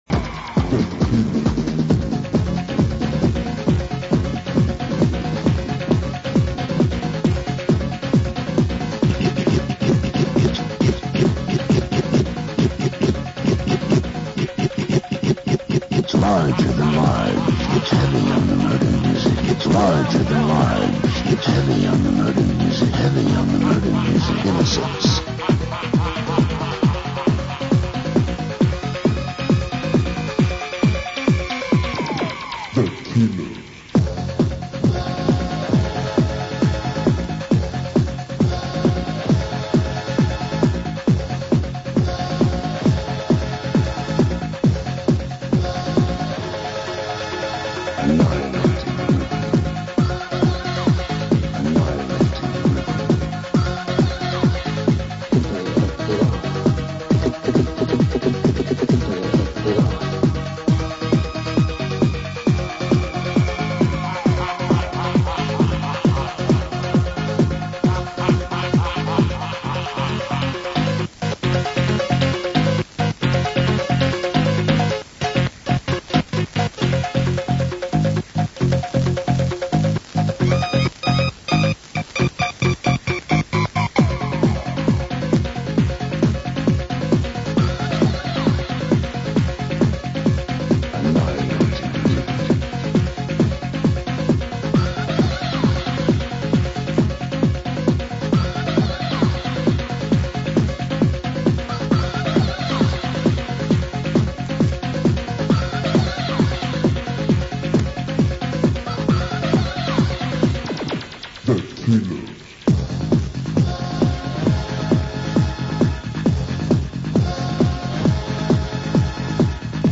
(quality a bit sus' on some tapes)
These tapes were recorded in Hammersmith, London and were some of the very first pirate radio stations I heard and recorded.
As with the Chillin' tapes, they had loads of adverts inbetween the music.